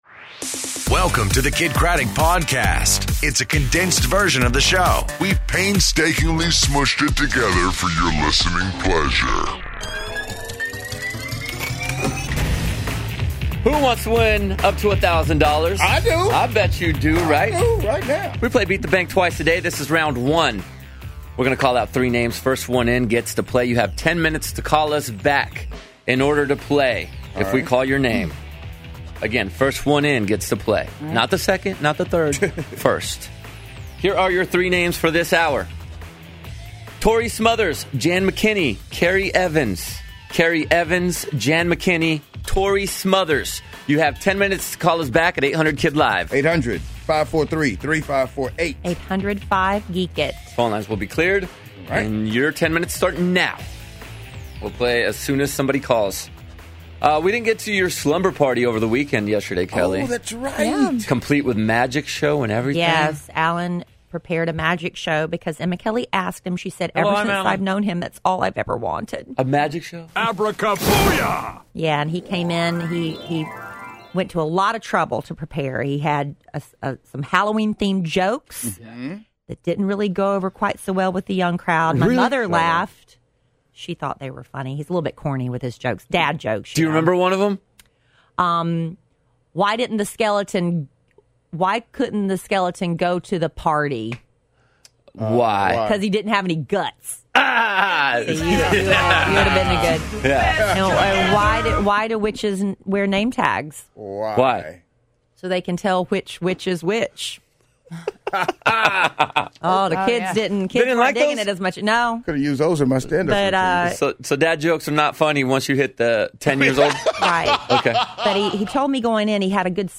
And Frenship Live In The Metro PCS Music Lounge